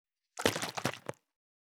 500のペットボトル,ペットボトル振る,ワインボトルを振る,水の音,ジュースを振る,シャカシャカ,カシャカシャ,チャプチャプ,ポチャポチャ,シャバシャバ,チャプン,ドボドボ,グビグビ,パシャパシャ,ザバザバ,ゴボゴボ,ジャブジャブ,
効果音